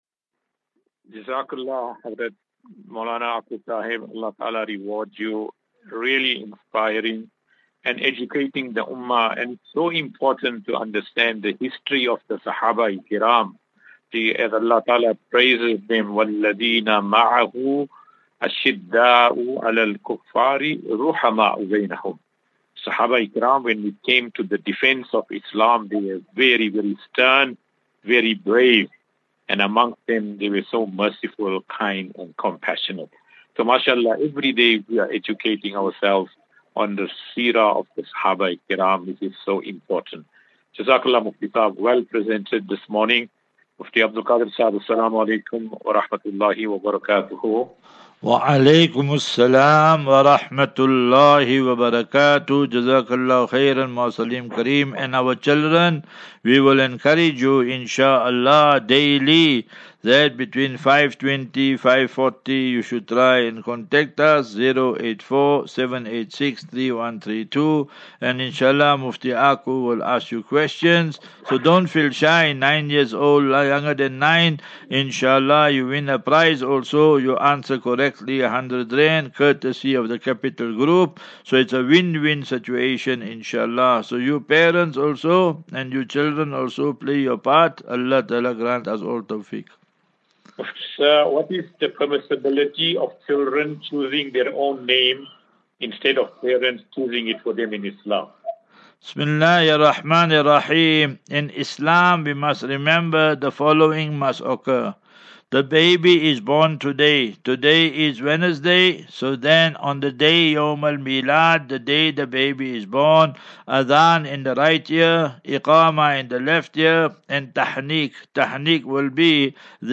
As Safinatu Ilal Jannah Naseeha and Q and A 20 Mar 20 March 2024.